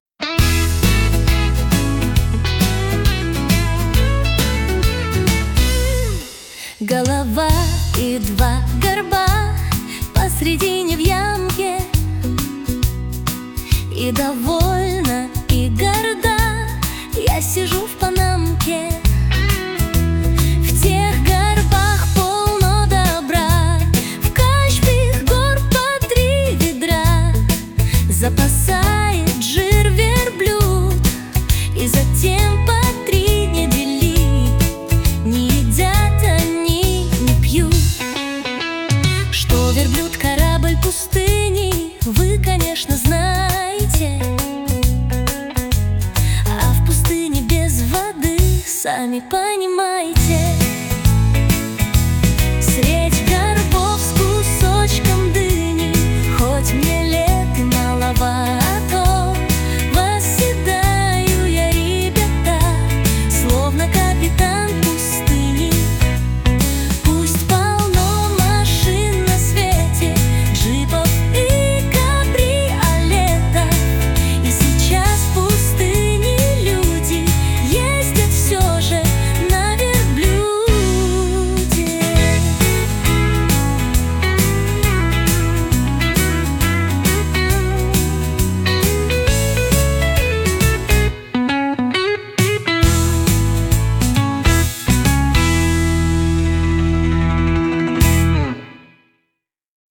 • Аранжировка: Ai
• Жанр: Детская